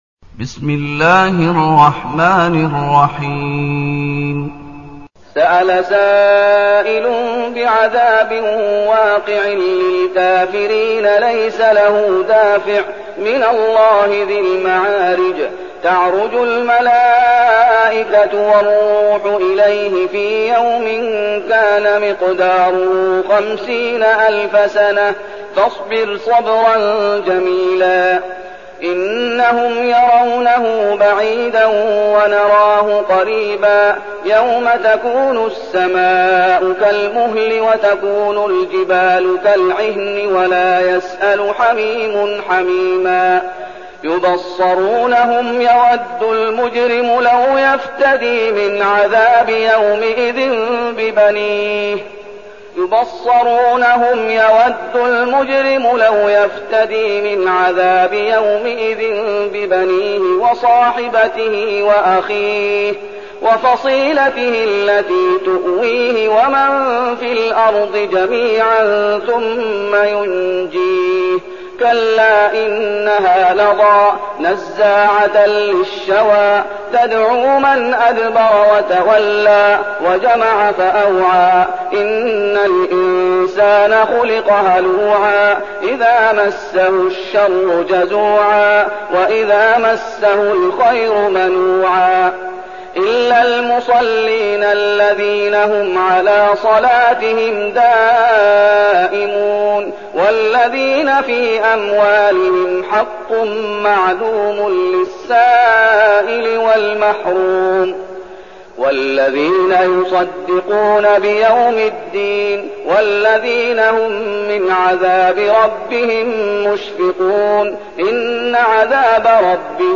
المكان: المسجد النبوي الشيخ: فضيلة الشيخ محمد أيوب فضيلة الشيخ محمد أيوب المعارج